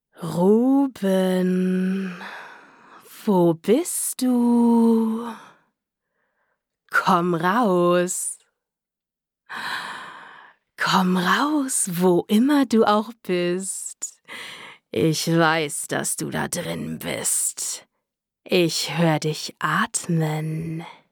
Charakter Psycho:
Stimmalter: ca. 18-35 Jahre